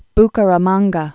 (bkə-rə-mänggə, -kä-rä-mänggä)